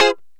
Lng Gtr Chik Min 01-F2.wav